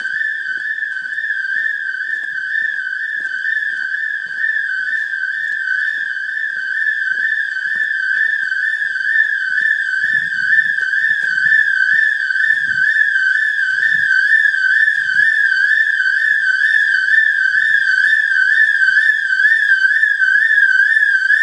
Alarm in barga